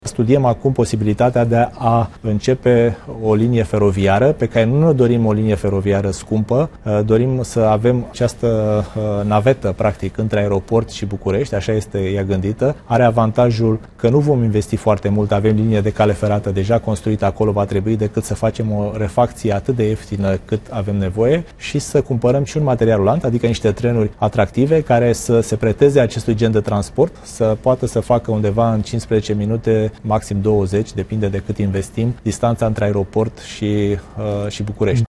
Declarația a fost făcută de ministrul Transporturilor, Dan Costescu într-un interviu la ProTV.